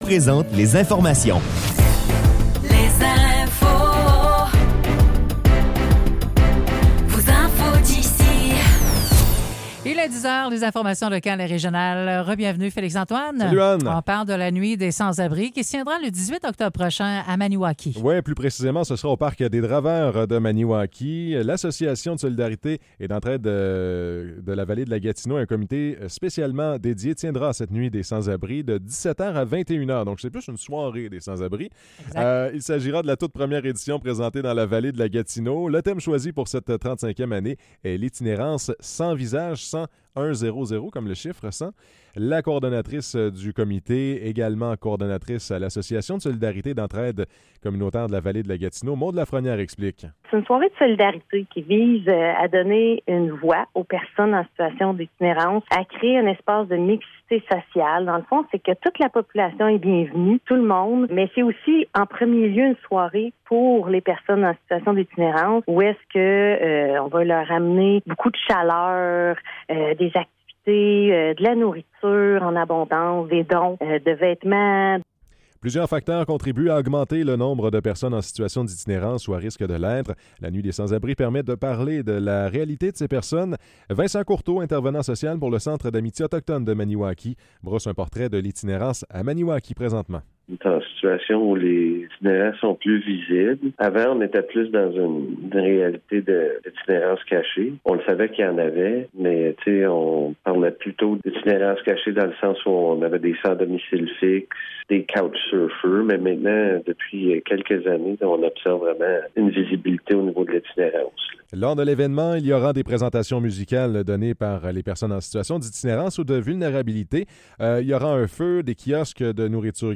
Nouvelles locales - 9 octobre 2024 - 10 h